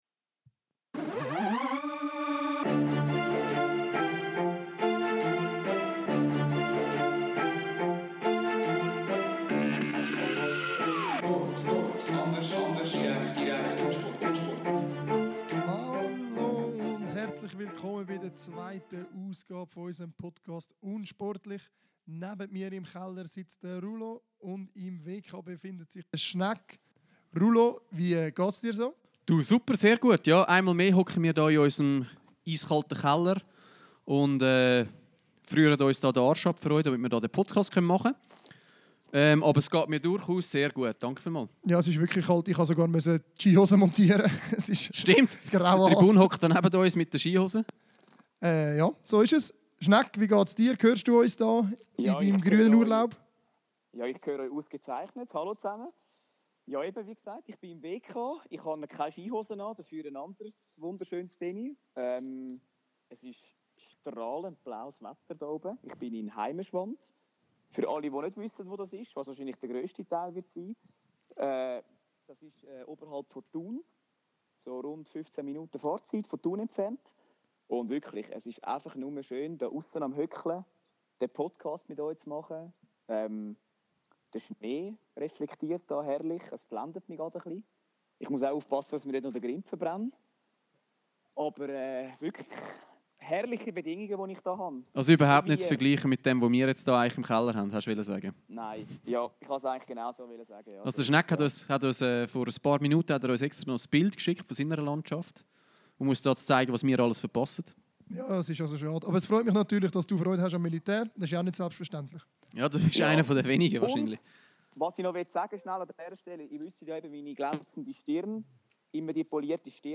Der Empfang im Bunker war scheisse!